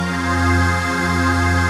VOICEPAD13-LR.wav